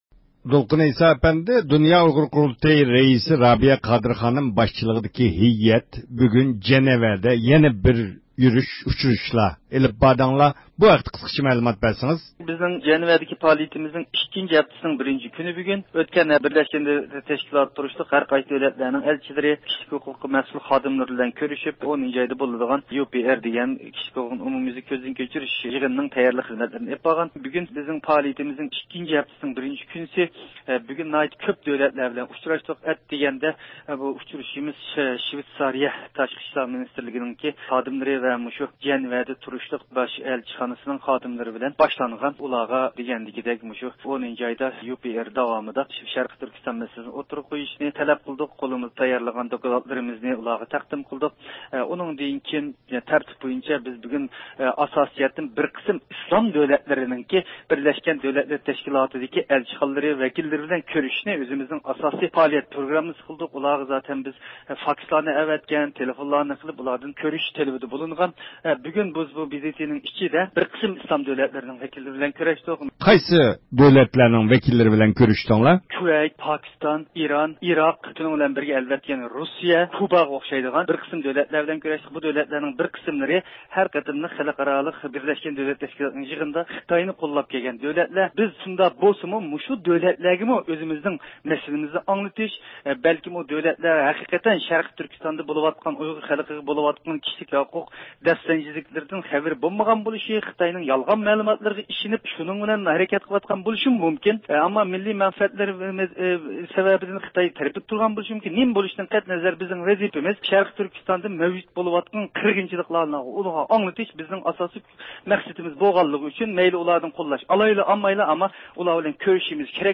تېلېفون سۆھبىتى